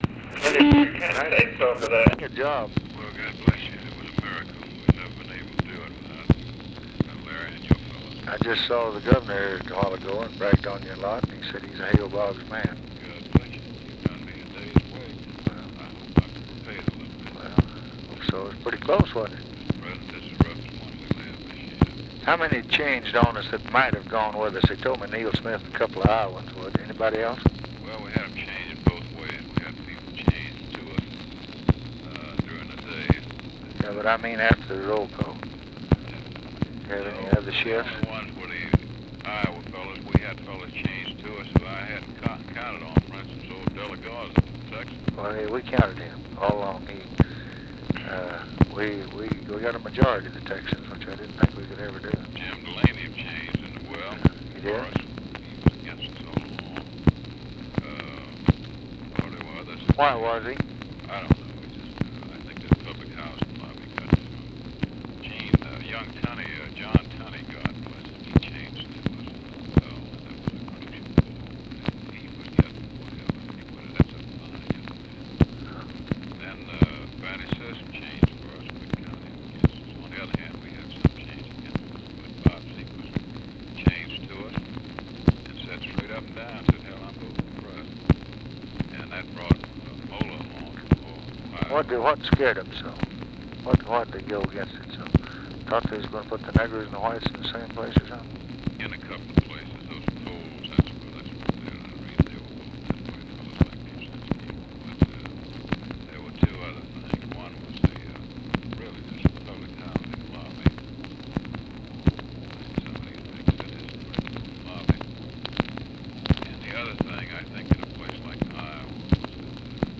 Conversation with HALE BOGGS, June 30, 1965
Secret White House Tapes